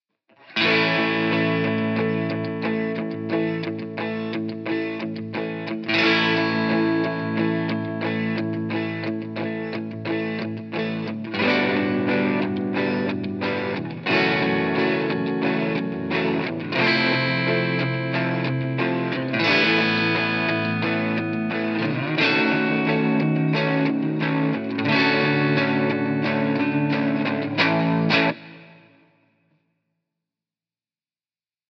Aというこの曲のキーである音から下降していく様も深い愛へとゆっくり落ちていくような展開です。
コード進行　A A GM7 F#m FM7 Aadd9 D#m7-5 DM7 A
コード　サンプル音源2